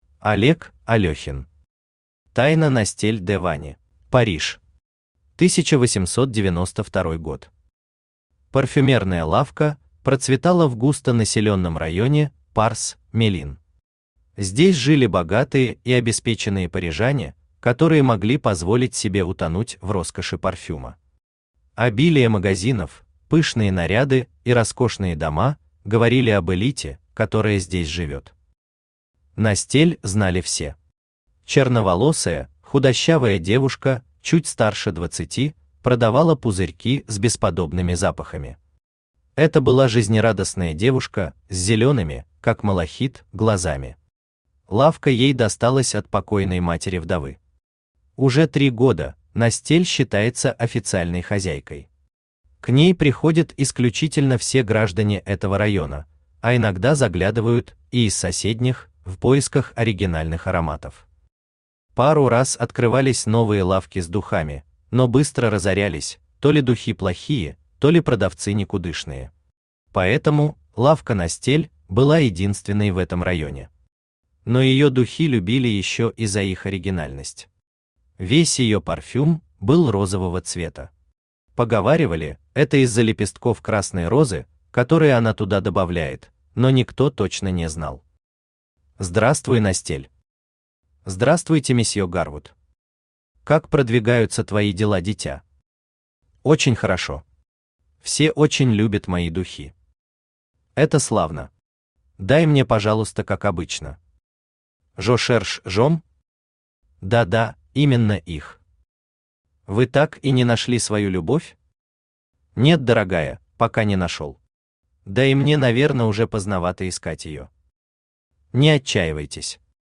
Аудиокнига Тайна Настель Де Вани | Библиотека аудиокниг
Aудиокнига Тайна Настель Де Вани Автор Олег Евгеньевич Алёхин Читает аудиокнигу Авточтец ЛитРес.